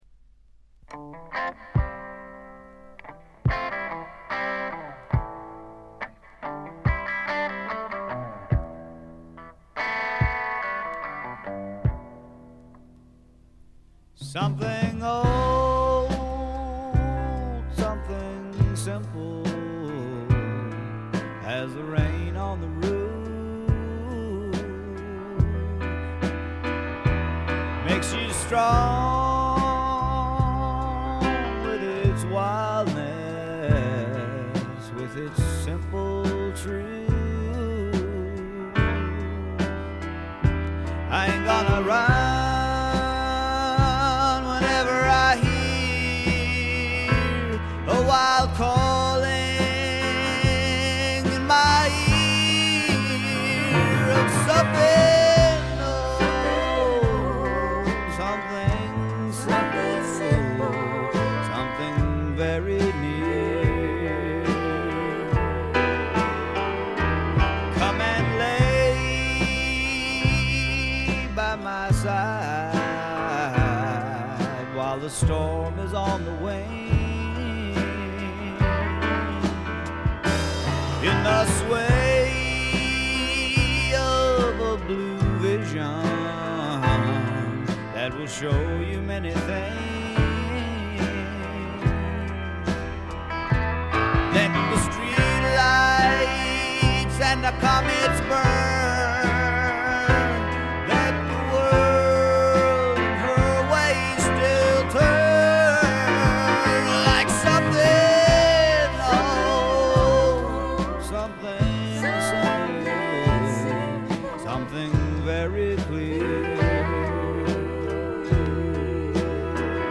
シンガーソングライター名盤中の名盤。
聴くものの心をわしづかみにするような渋みのある深いヴォーカルは一度聴いたら忘れられません。
試聴曲は現品からの取り込み音源です。